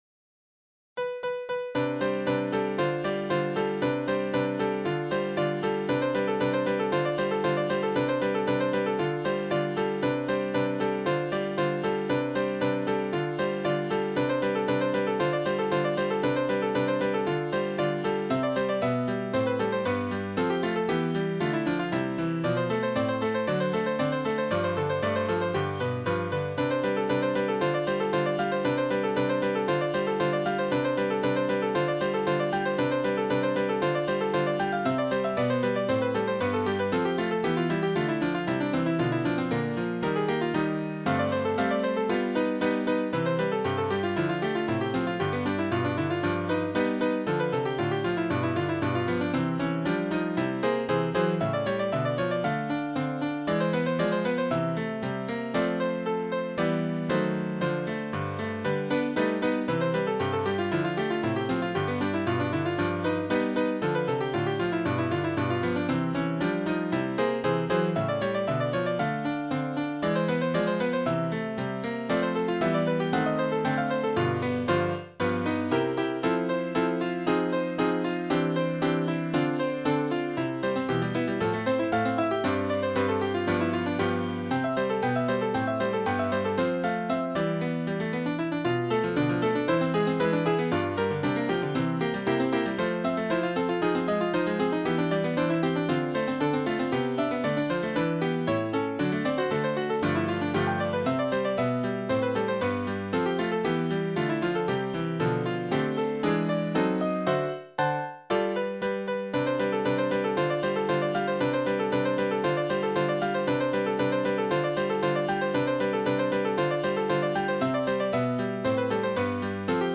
ピアノソナタNo.1 in C dur
オリジナルのピアノソナタです。